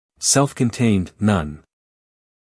英音/ ˌself kənˈteɪnd / 美音/ ˌself kənˈteɪnd /